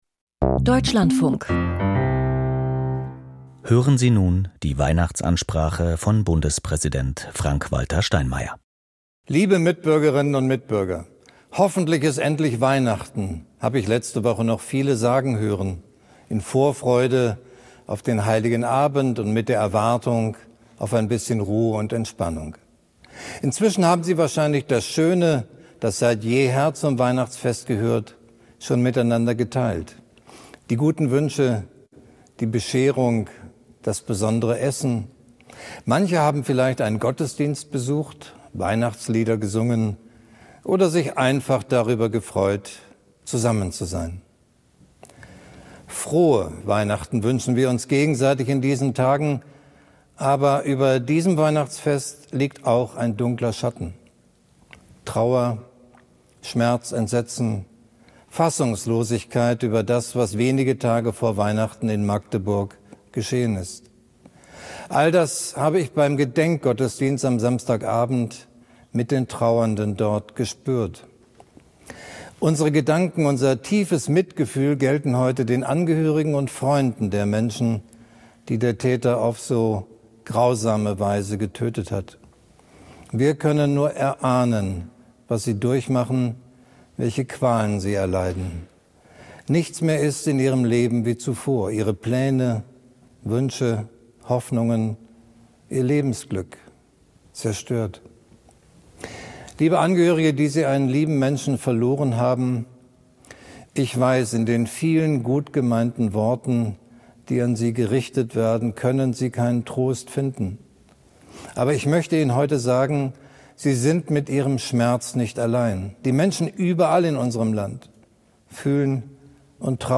Weihnachtsansprache von Bundespräsident Frank-Walter Steinmeier